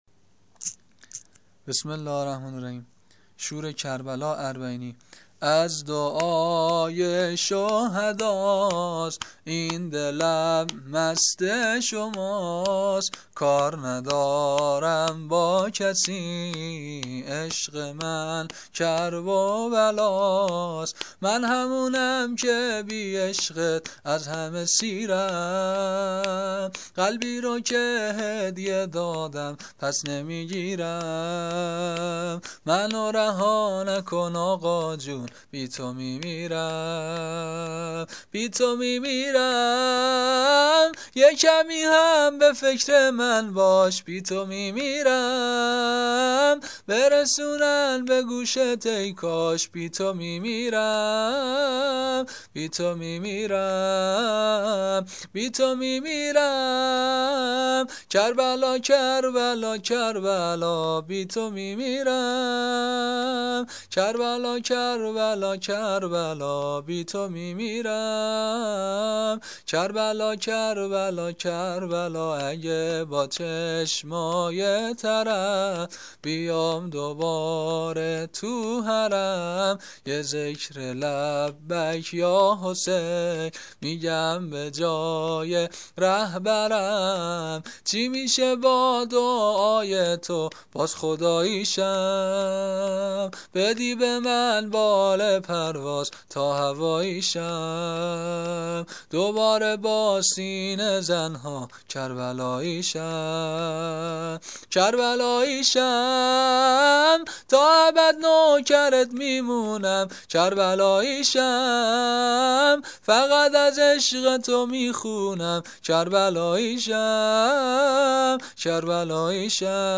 شور 1